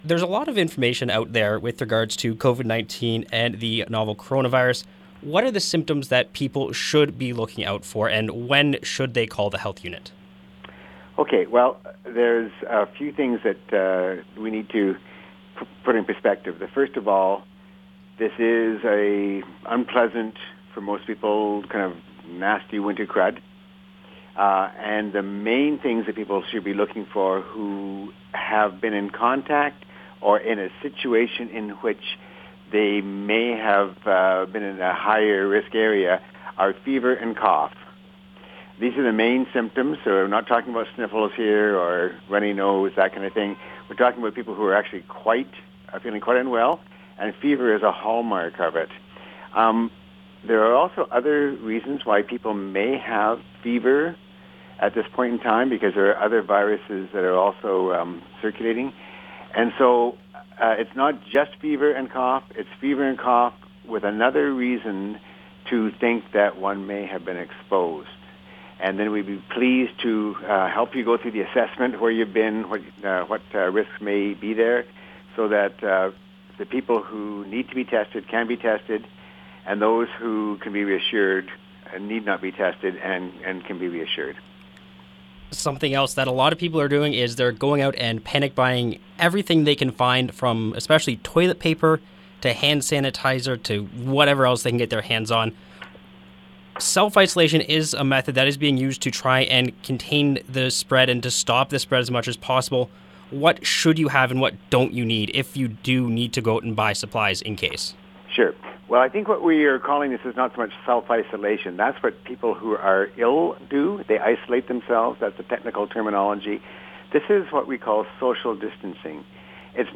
To hear the complete interview with Dr. Ian Gemmill, visit the Audio link below.